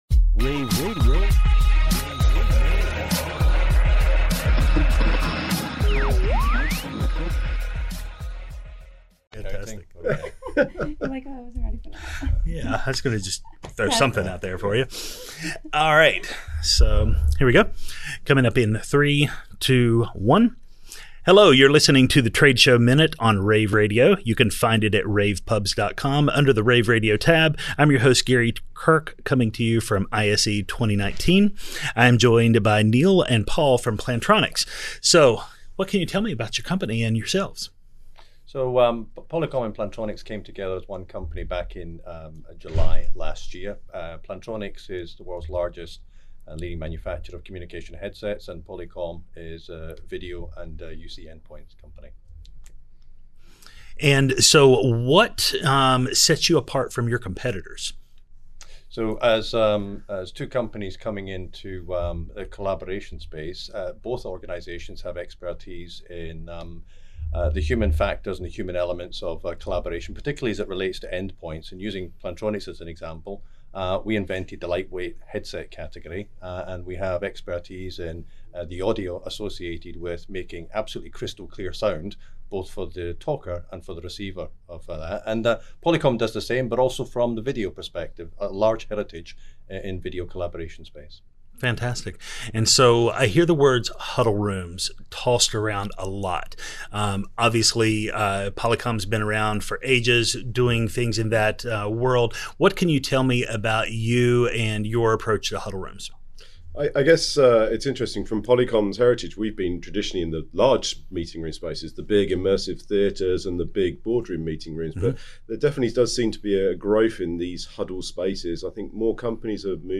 February 5, 2019 - ISE, ISE Radio, Radio, rAVe [PUBS], The Trade Show Minute,